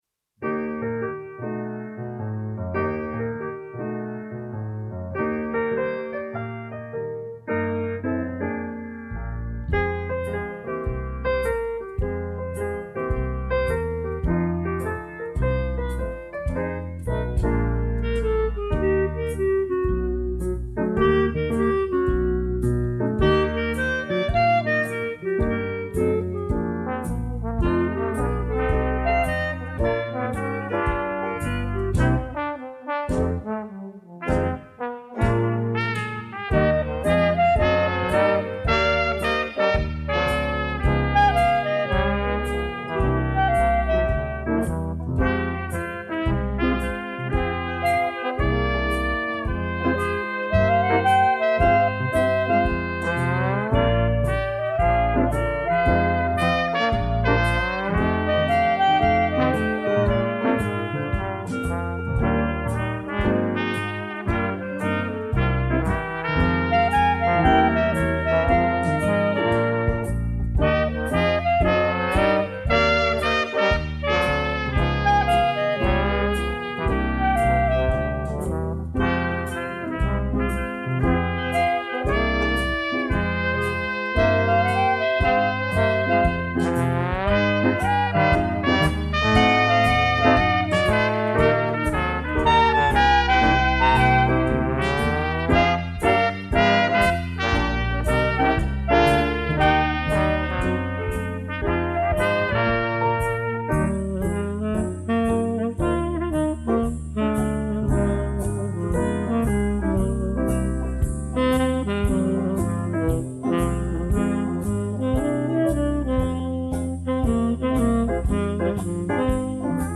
Gattung: Dixieland Combo
Besetzung: Dixieland Combo